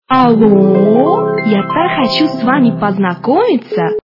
» Звуки » Смешные » Алоооо! - Я так хочу с Вами познакомиться